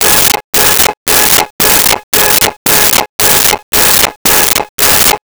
Car Alarm 02
Car Alarm 02.wav